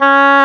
WND OBOE-A.wav